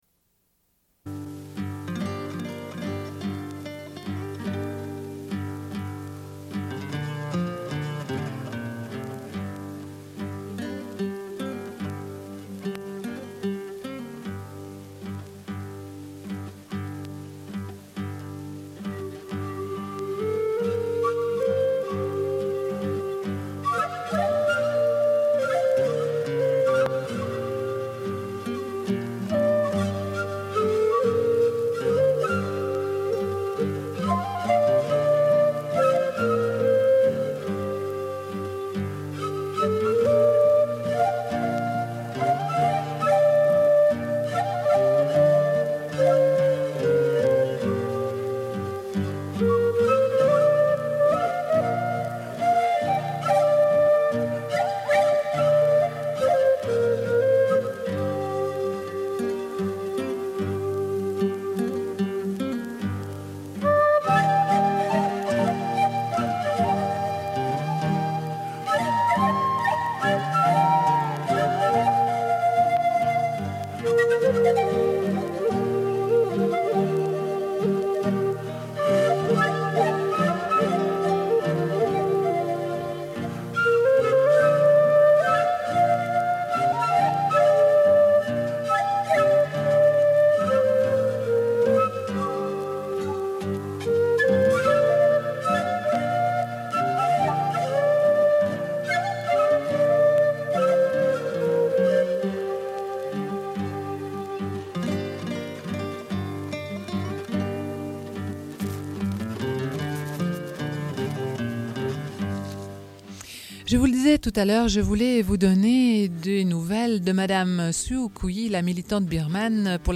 Une cassette audio, face A31:48
Suite de l'émission sur la militante des droits humains Rigoberta Menchù, Prix Nobel de la Paix 1992. Lecture d'un article et extraits de son livre Moi Rigoberta. Une vie et une voix, la révolution au Guatemala.